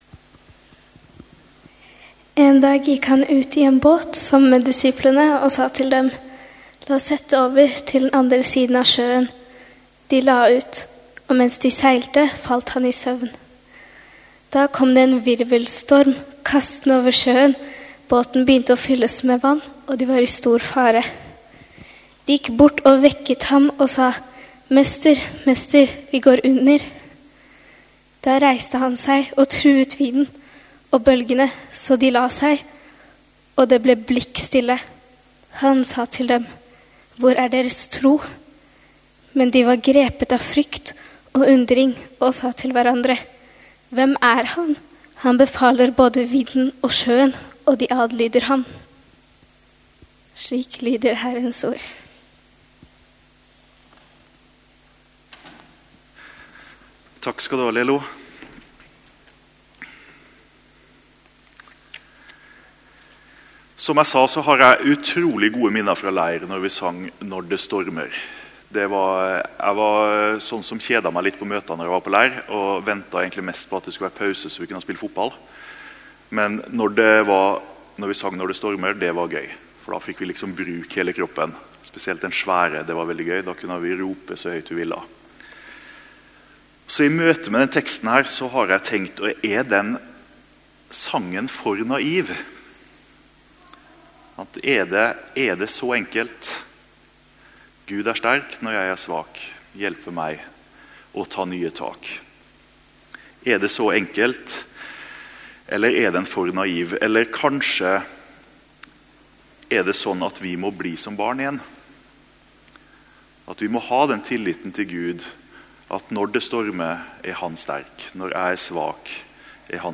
Gudstjeneste morgen 19. september 2021,- møte Gud i uroen | Storsalen